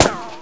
ricochet sounds